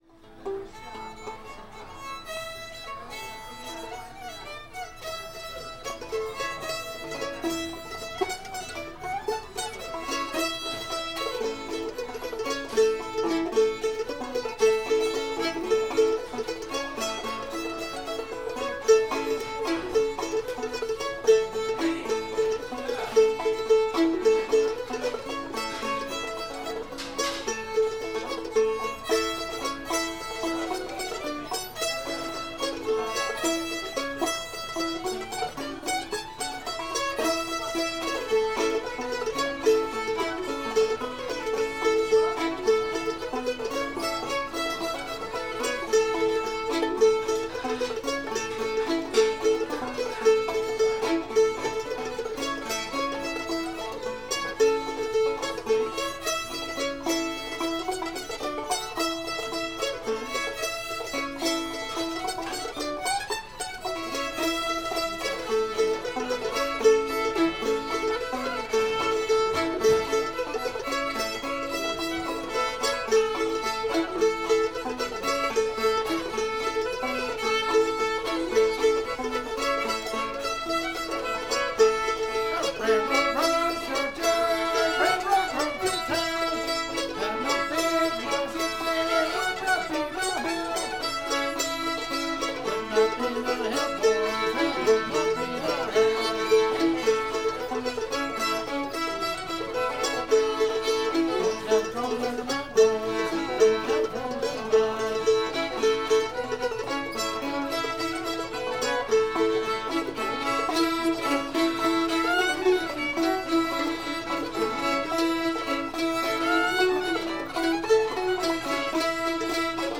railroad runs through georgia [A]